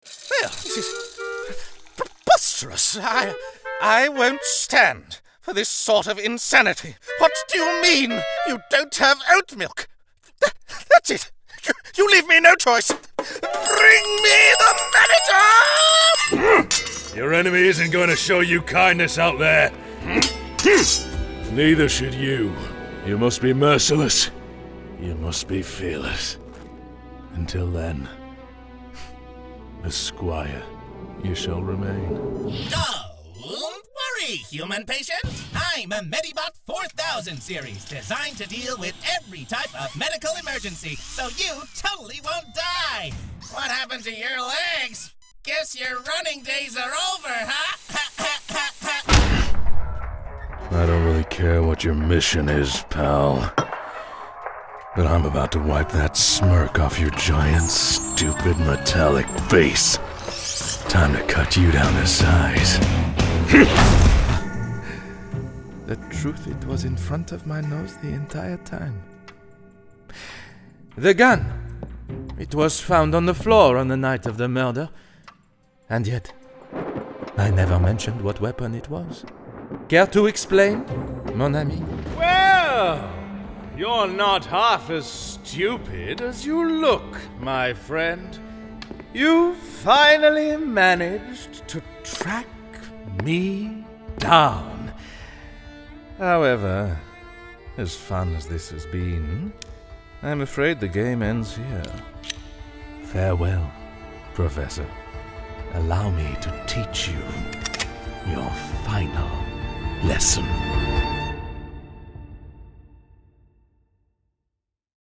Demo
Young Adult, Adult
Has Own Studio
british rp | character
british rp | natural